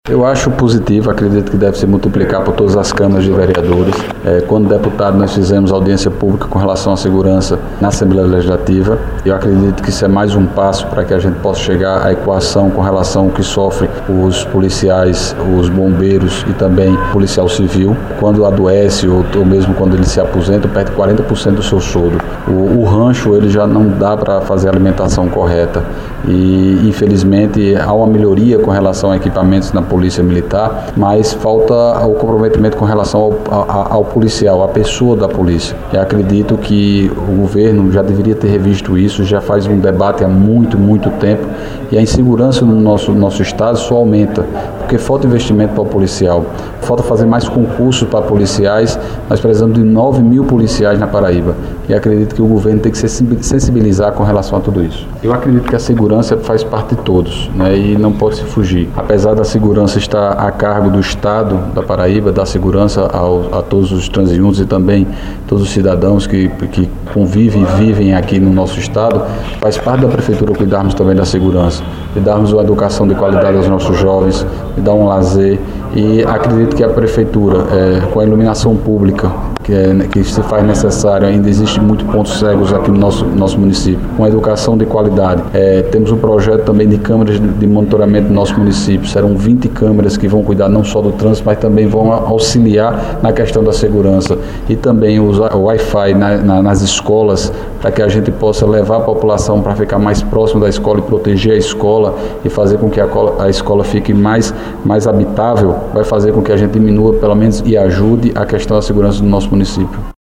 Dinaldinho usou a tribuna da Casa Legislativa para apresentar informações referentes à cidade de Patos, no que diz respeito a segurança Pública no município.
Fala do prefeito Dinaldinho Wanderley –